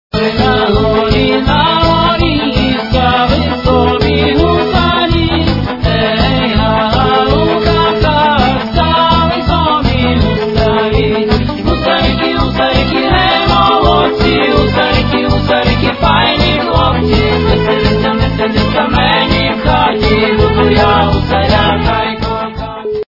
» Реалтоны » народные » Украинская народная песня